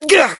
hotshot_hurt_08.ogg